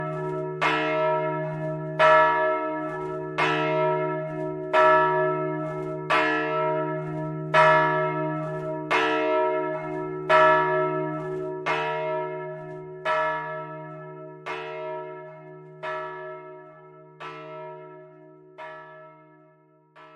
Ook de klokken van Heel hebben door de samenstelling van het materiaal een warme klankkleur en een lange uitklinktijd (nagalm).
De grote klok
Ze luidt diep, warme klanken die ver dragen.
Luister naar het luiden van de grote klok.
Kerkklok-Heel-Grote-klok-Maria.mp3